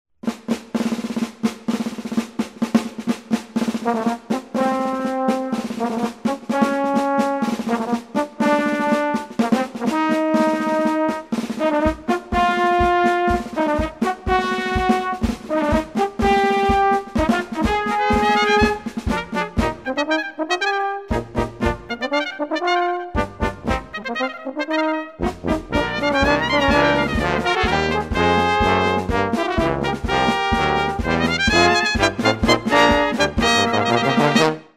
6-piece Ensemble
Baritone, Banjo
Trombone
Tuba
Drums